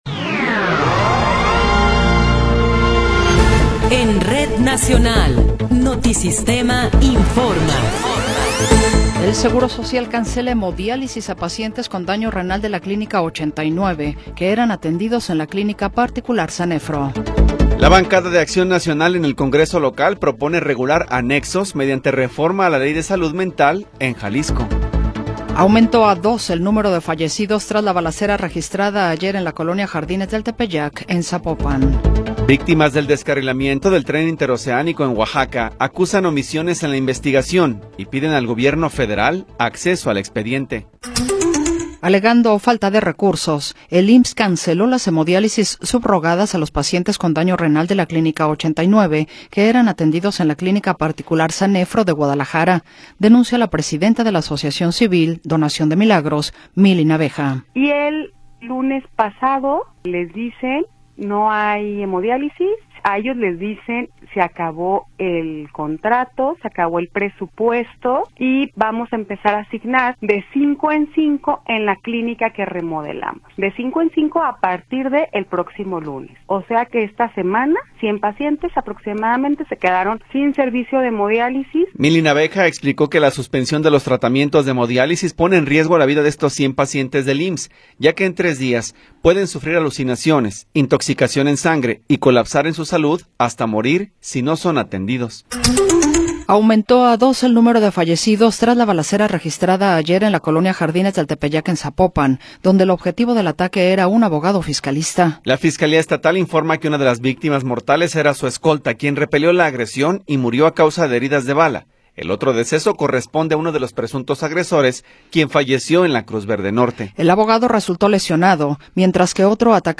Noticiero 14 hrs. – 28 de Enero de 2026
Resumen informativo Notisistema, la mejor y más completa información cada hora en la hora.